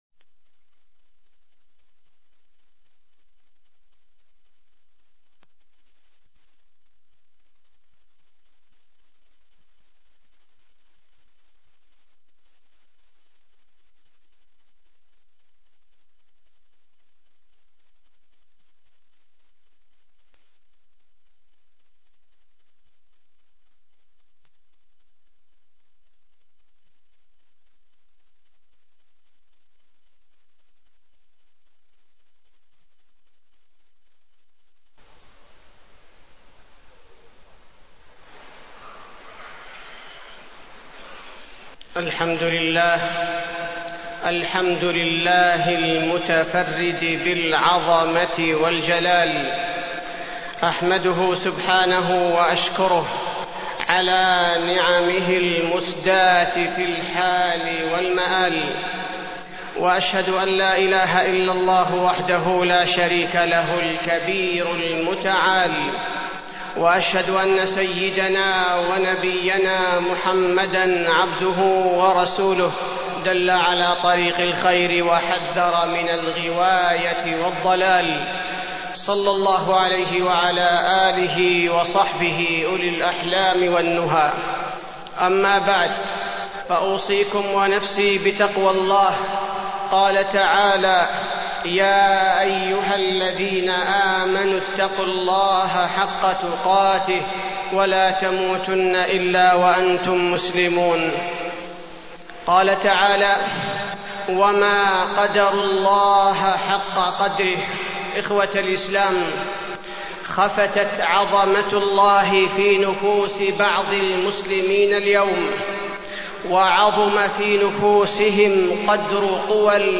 تاريخ النشر ٢٥ جمادى الأولى ١٤٢٤ هـ المكان: المسجد النبوي الشيخ: فضيلة الشيخ عبدالباري الثبيتي فضيلة الشيخ عبدالباري الثبيتي عظم قدرة الله